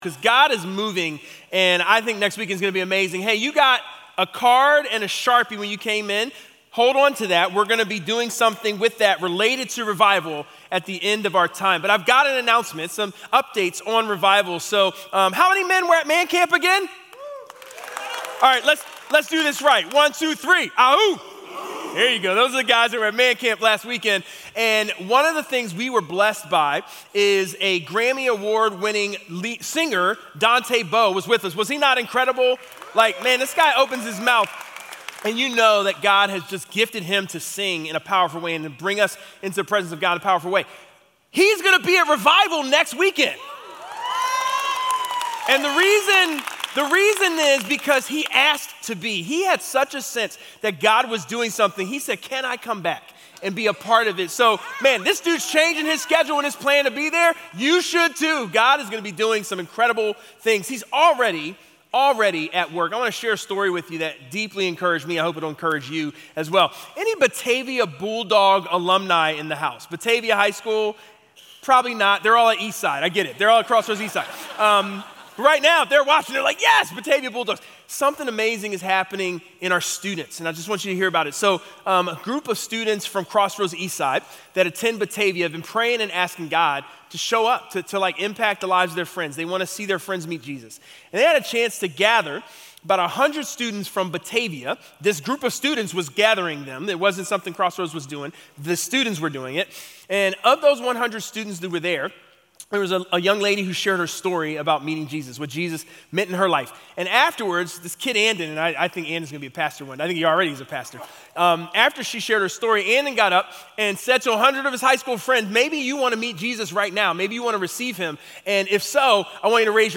Recorded live at Crossroads Church in Cincinnati, Ohio.